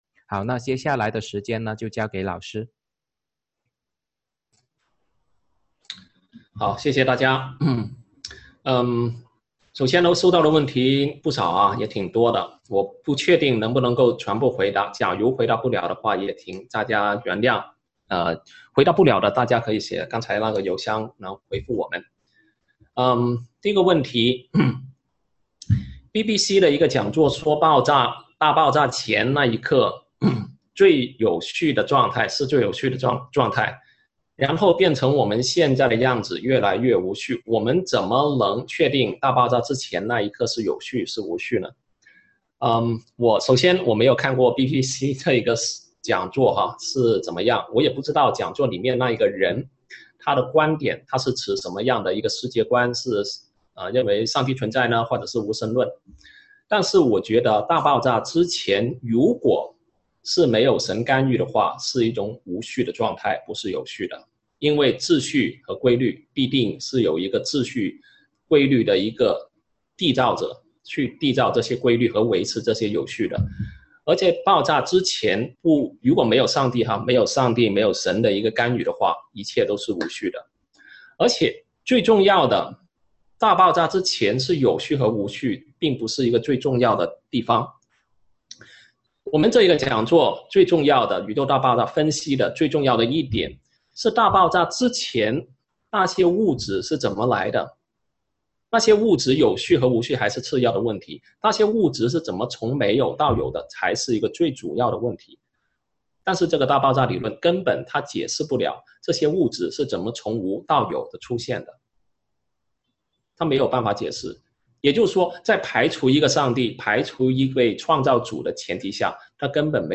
《宇宙大爆炸与精细微调》讲座直播回放